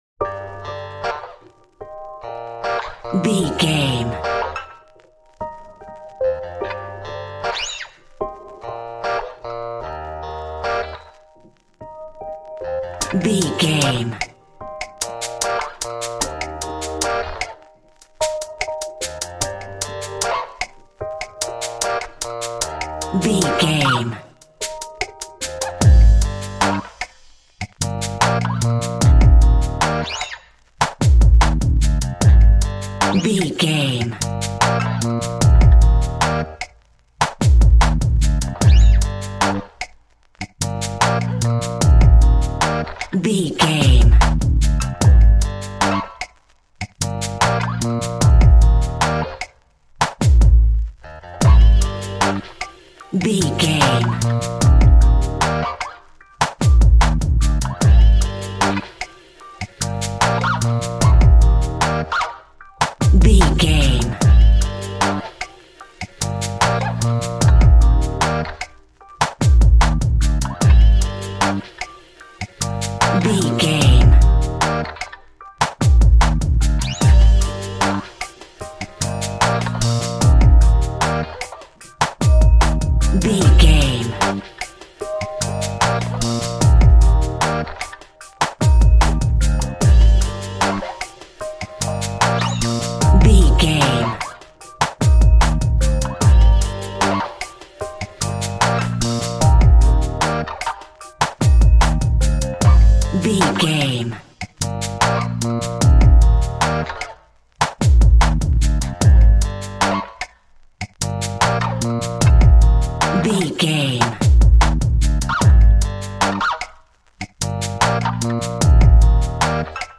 Aeolian/Minor
Slow
dreamy
tranquil
repetitive
acoustic guitar
synthesiser
bass guitar
drum machine
vocals
electric piano